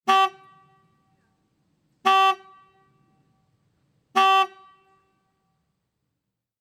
Мотоциклетный сигнал